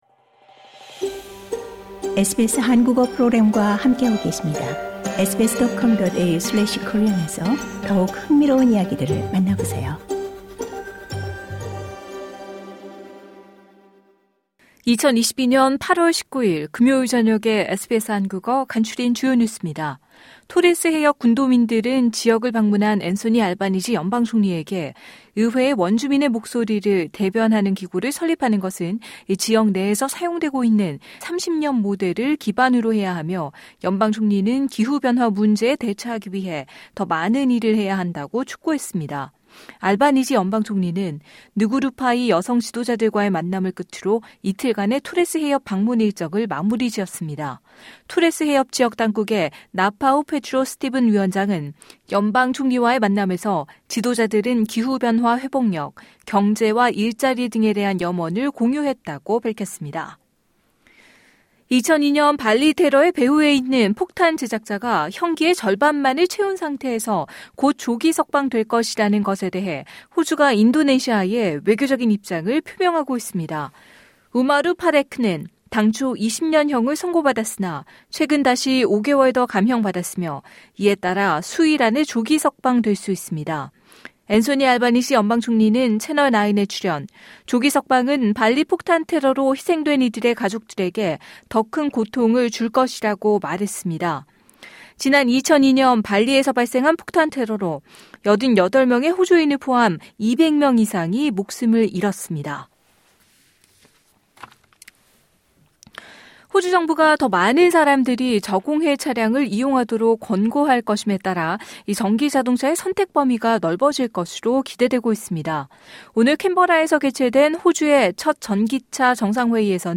SBS 한국어 저녁 뉴스: 2022년 8월 19일 금요일